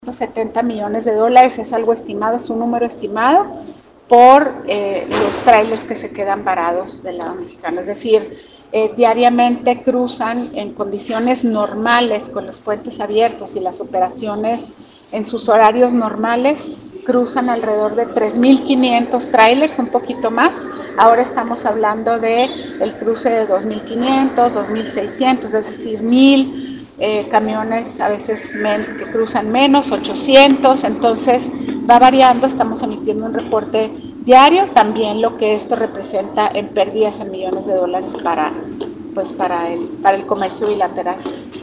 AUDIO: MARÍA ANGÉLICA GRANADOS, SECRETARÍA DE INNOVACIÓN Y DESARROLLO ECONÓMICO